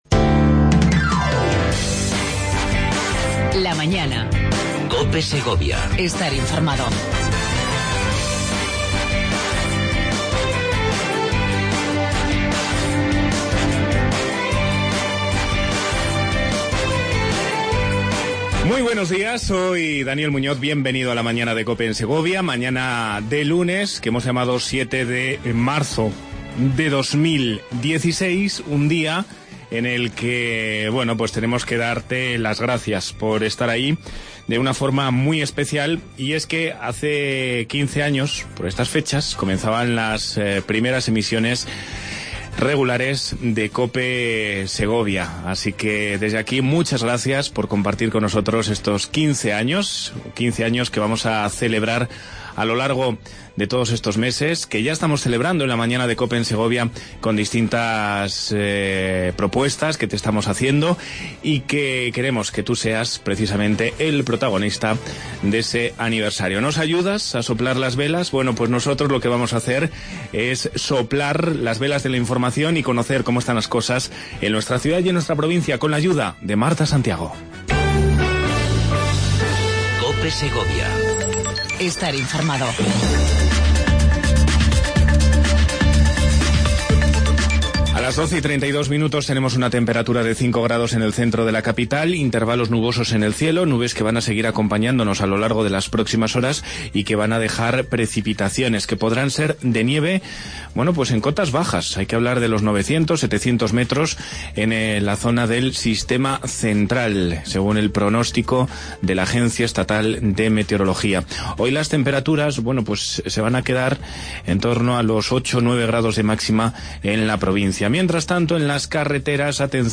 AUDIO: Entrevista con Francisco Vazquez, presidente de la Diputación Provincial de Segovia.